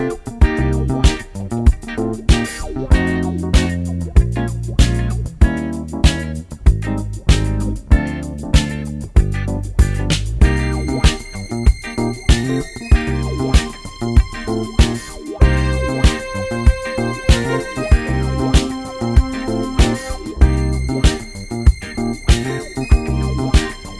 no Backing Vocals Disco 3:23 Buy £1.50